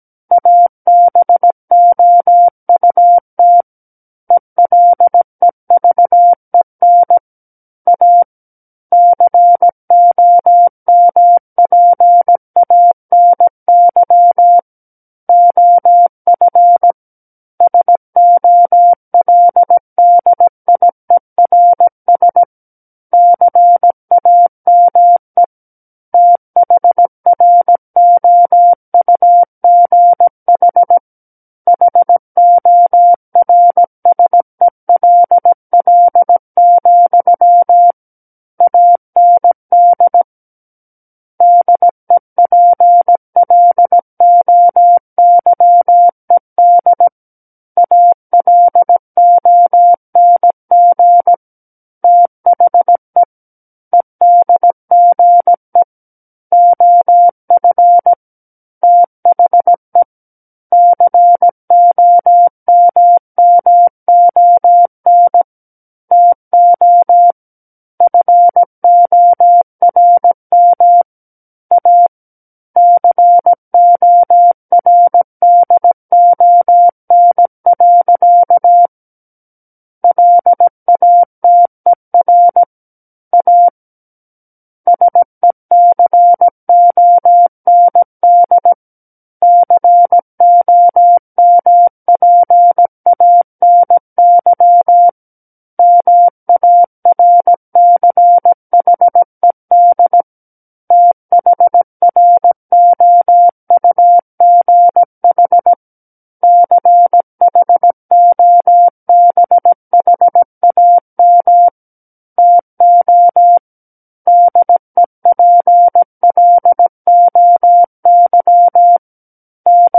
SKCC Learning Center - Morse Code Practice Files
War of the Worlds - 08-Chapter 8 - 17 WPM